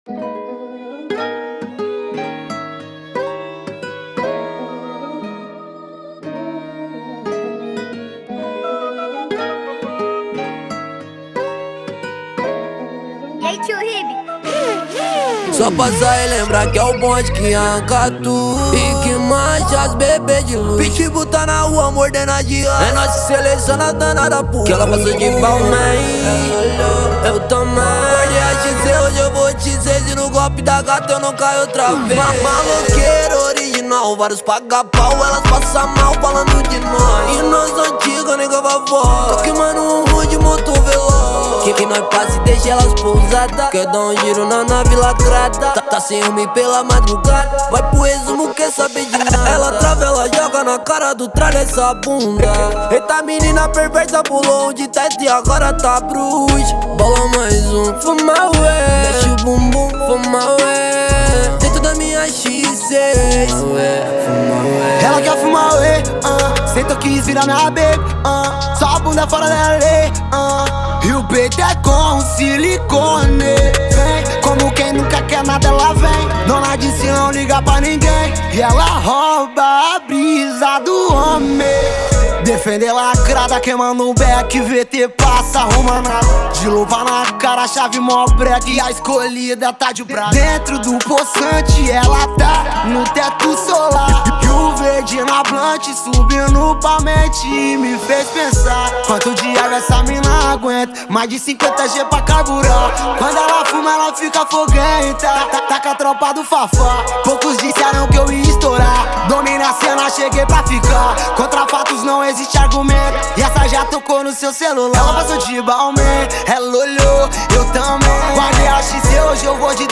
Baixar Funk